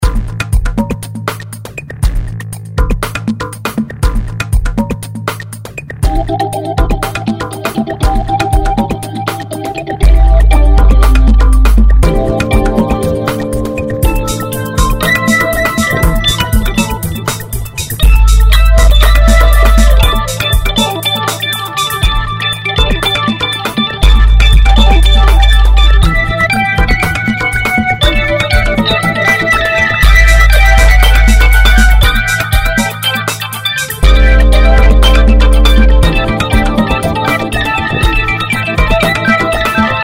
Jingles to listen to:
synthétiseur, chant, guitare, percussion, drums